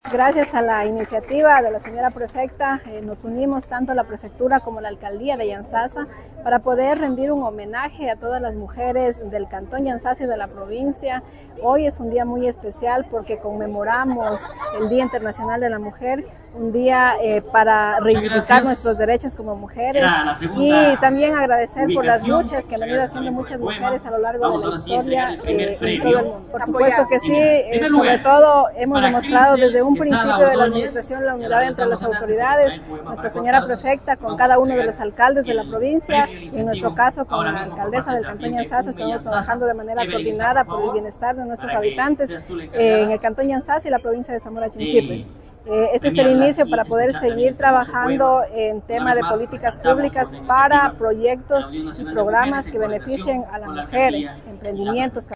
“Es un día para recordarnos a nosotras mismas, porque hemos venido dejando huella en todo nuestro caminar, invito a seguir con la misma fuerza en busca de mejores ideales…”, sostuvo María Lalangui, la alcaldesa de Yantzaza durante el acto inaugural.
MARÍA LALANGUI, ALCALDESA YANTZAZA